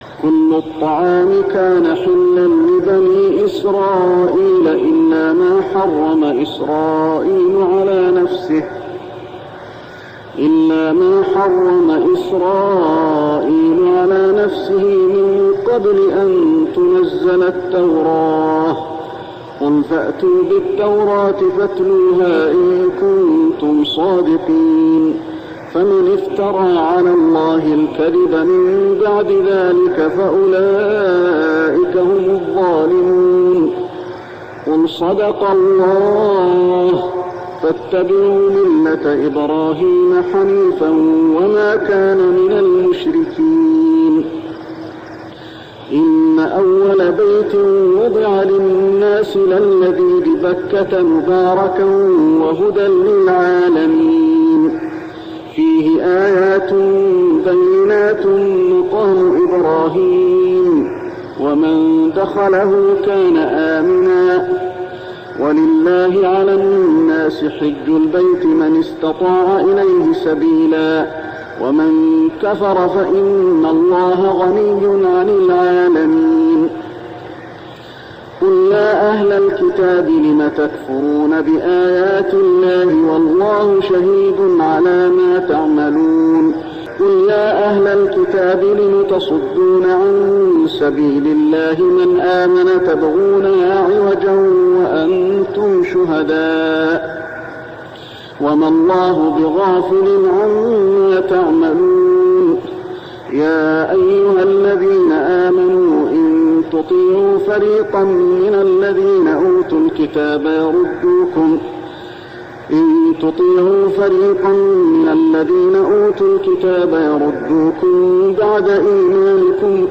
صلاة التراويح ليلة 4-9-1409هـ سورة آل عمران 93-168 | Tarawih prayer Surah Al-Imran > تراويح الحرم المكي عام 1409 🕋 > التراويح - تلاوات الحرمين